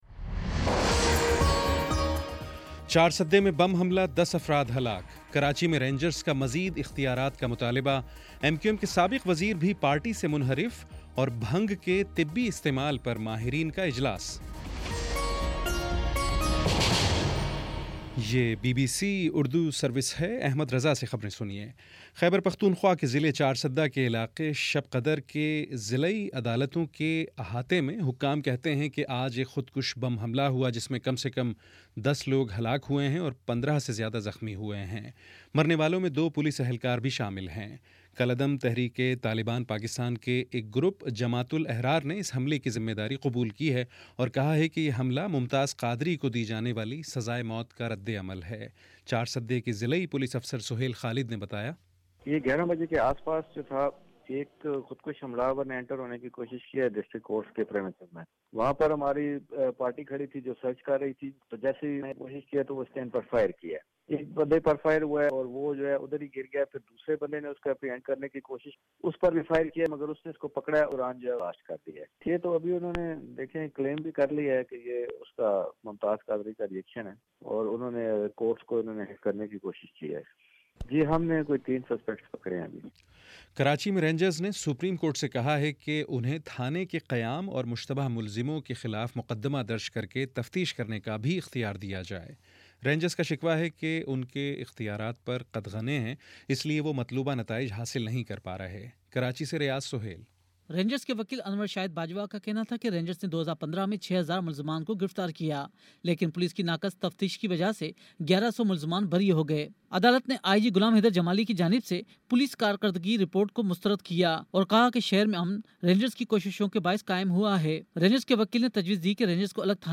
مارچ 07 : شام چھ بجے کا نیوز بُلیٹن